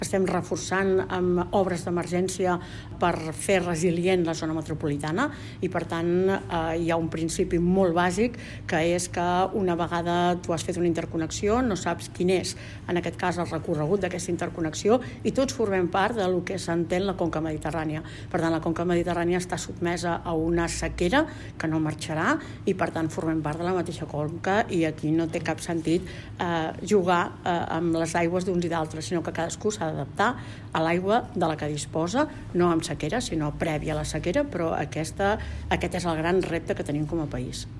La secretària d’Acció Climàtica de la Generalitat, Anna Barnadas ha reiterat que el Govern ha descartat la interconnexió de conques de l’Ebre i el Ter-Llobregat com a solució a l’episodi de sequera que viu el país. Amb una trobada amb periodistes ebrencs, la responsable d’Acció Climàtica ha insistit que l’executiu treballa perquè les conques internes i per tant, també l’àrea metropolitana de Barcelona siguin resilients amb els recursos hídrics de què disposen.